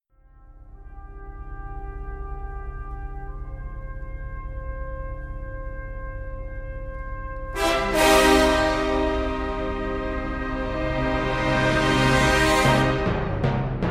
crescendo.mp3